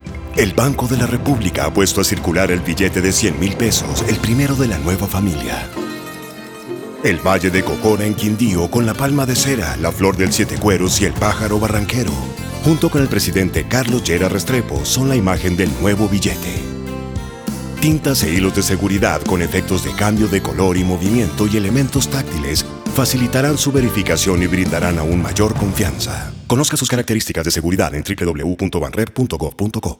Cuña radial
100-radial_0.wav